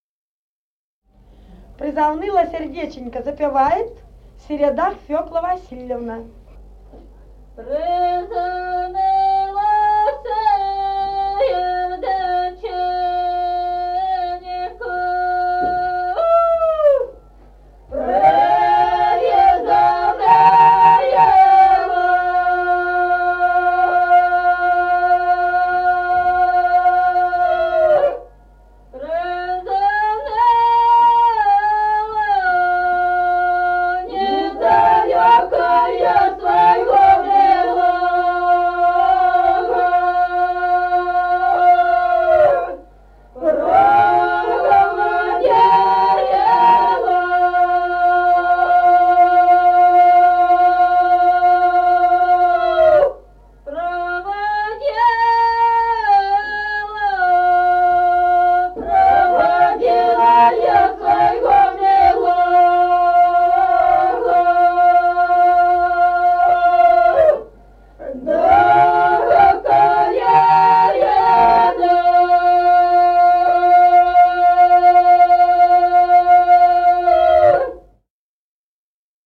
Песни села Остроглядово. Призавныло сердеченько.